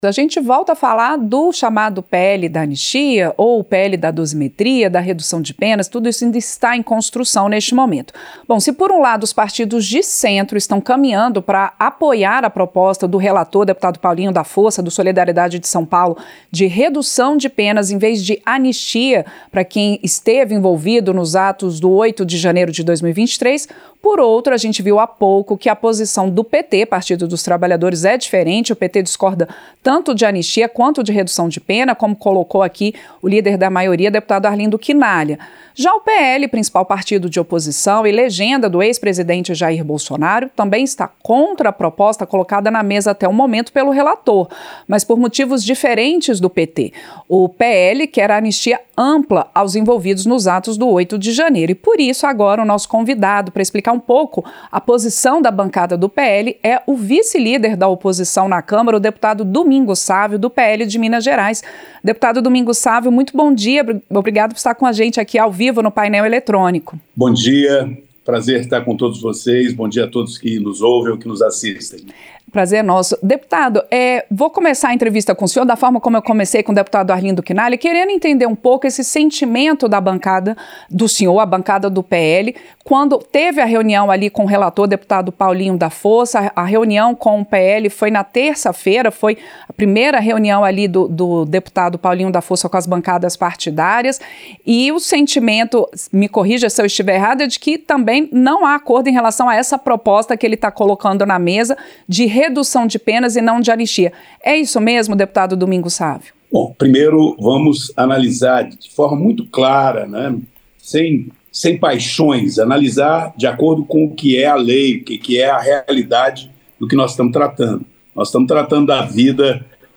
Entrevista - Dep. Domingos Sávio (PL-MG)